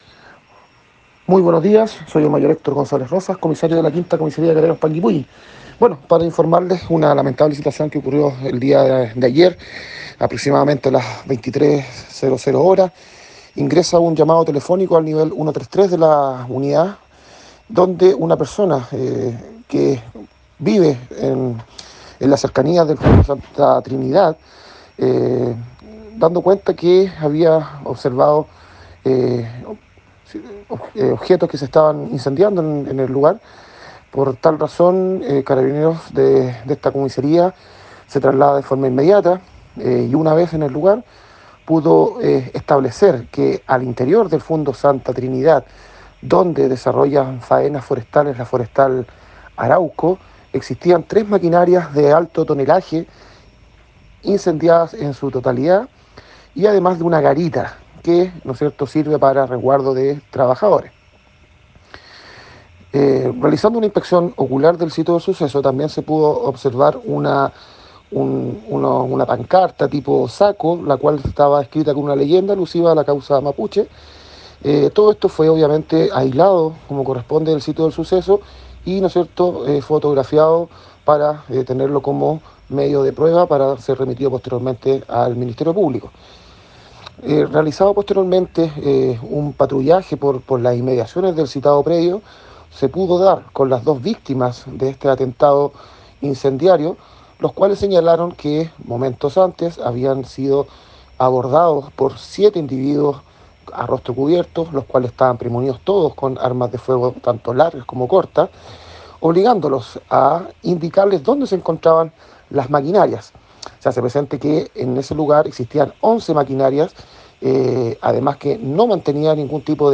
Comisario PDI..